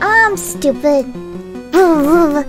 Worms speechbanks
ouch.wav